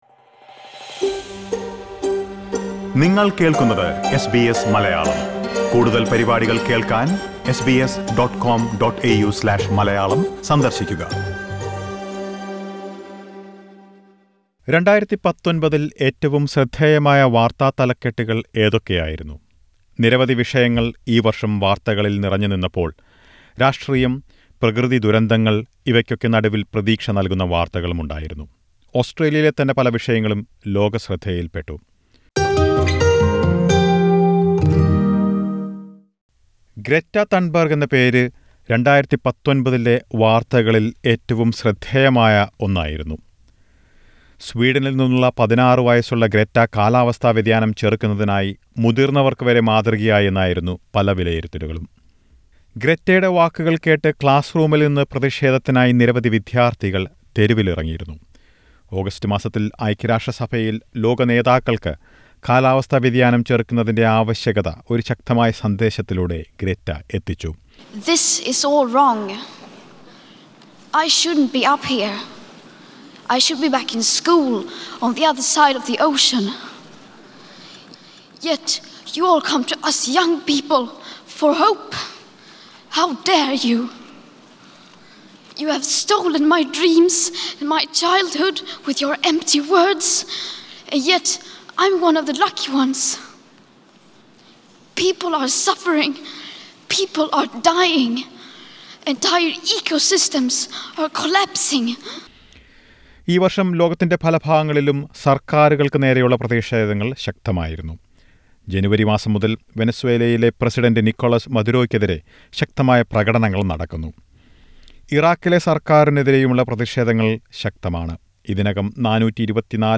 കാലാവസ്ഥാ വ്യതിയാനം, ഭീകരാക്രമണങ്ങൾ, സർക്കാറുകൾക്ക് നേരെയുള്ള പ്രതിഷേധങ്ങൾ തുടങ്ങി വിവിധ വാർത്തകൾ നിറഞ്ഞതായിരുന്നു പോയ വർഷം. 2019ലെ പ്രധാന വാർത്താ തലക്കെട്ടുകൾ ഉൾപ്പെടുത്തിയ ഒരു റിപ്പോർട്ട് കേൾക്കാം മുകളിലെ പ്ലെയറിൽ നിന്ന്.